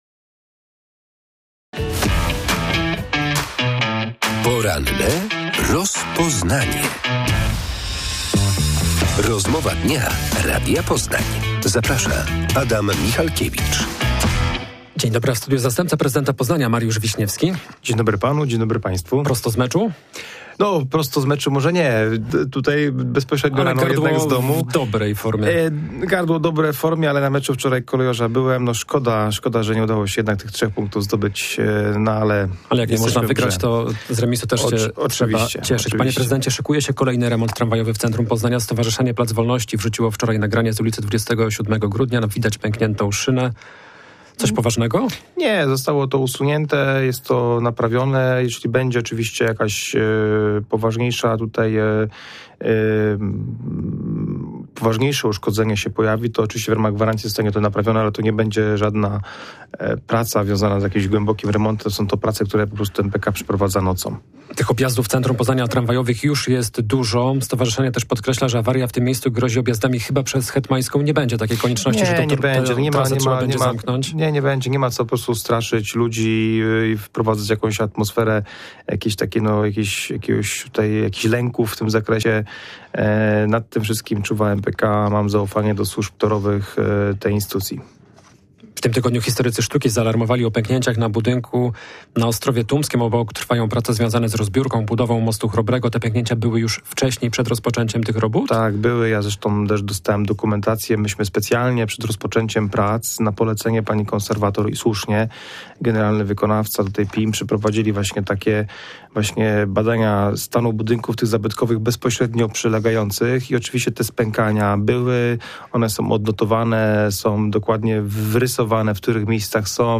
Rozmowa o remontach w Poznaniu z zastępcą prezydenta Poznania Mariuszem Wiśniewskim.